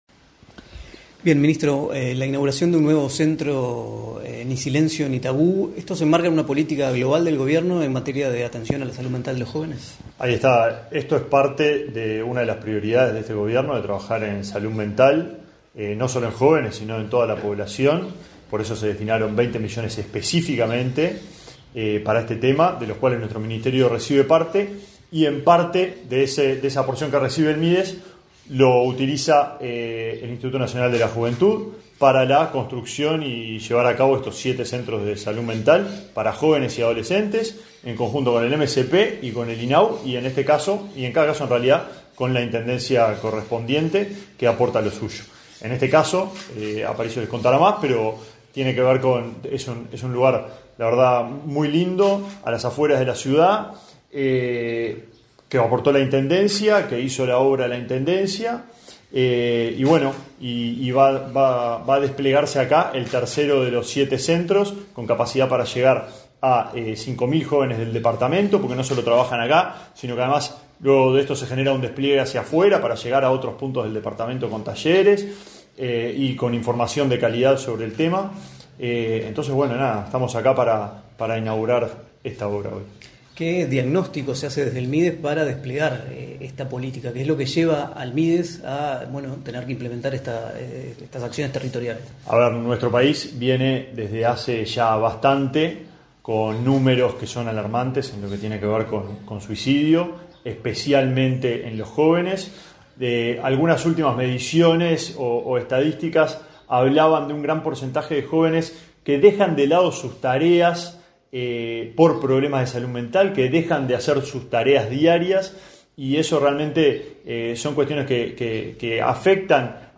Entrevista al ministro del Mides, Alejandro Sciarra
Entrevista al ministro del Mides, Alejandro Sciarra 31/10/2024 Compartir Facebook X Copiar enlace WhatsApp LinkedIn El Ministerio de Desarrollo Social (Mides), a través Instituto Nacional de la Juventud, inauguró, este 31 de octubre, el centro Ni Silencio Ni Tabú, en la ciudad de Florida. En la oportunidad, el ministro Alejandro Sciarra realizó declaraciones a Comunicación .